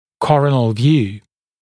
[‘kɔrənl vjuː][‘корэнл вйу:]вид сверху (головы, лица), коронарная проекция